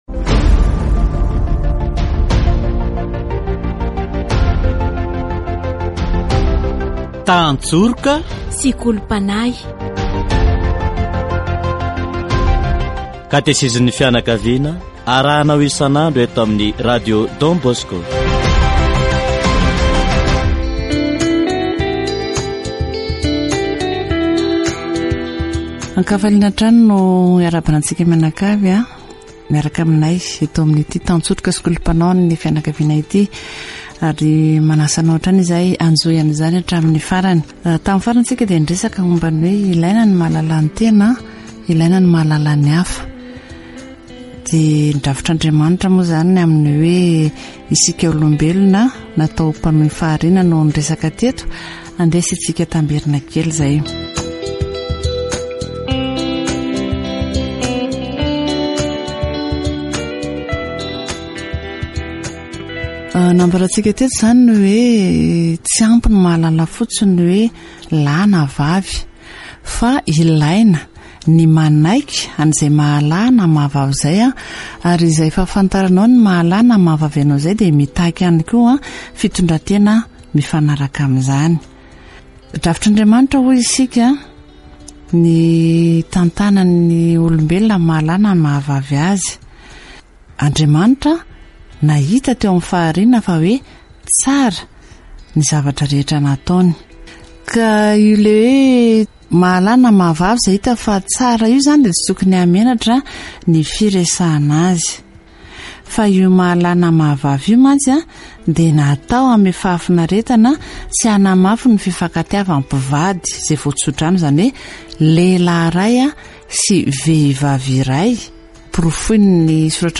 Faut-il un diplôme pour se marier ? mais les jeunes qui ont négligé leur éducation éduqueront-ils leurs enfants et surveilleront-ils l'éducation de leurs enfants ?. Les connaissances sont utilisées pour améliorer la vie d'une famille. Catéchèse concernant les jeunes se préparant au mariage